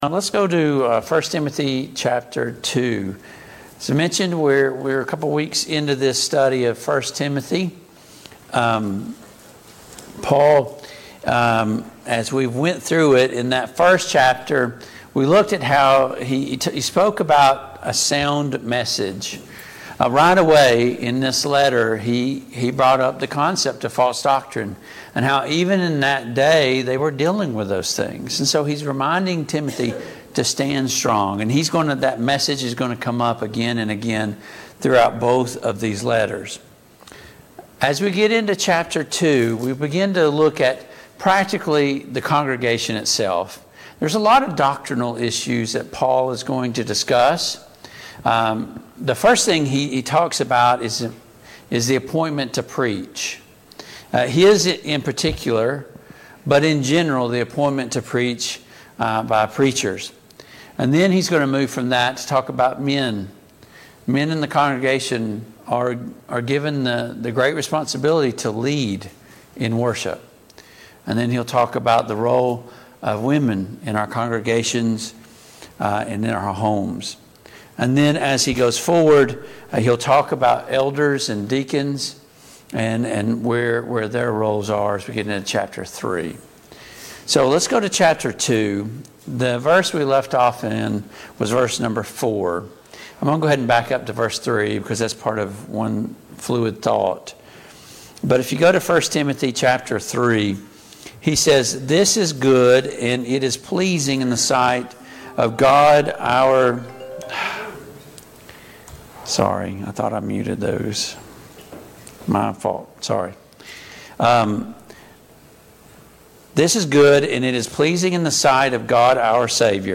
Passage: 1 Timothy 2:1-15 Service Type: Mid-Week Bible Study